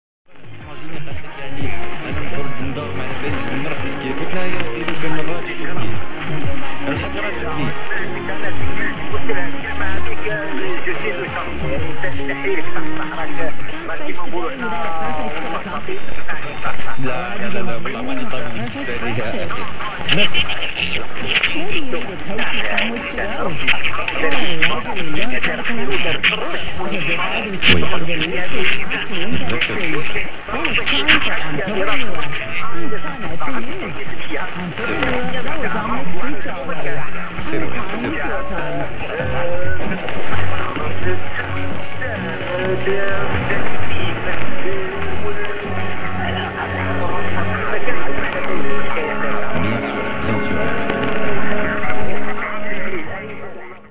Loggings from Quoddy House [QH] near Lubec, ME